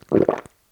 drink.wav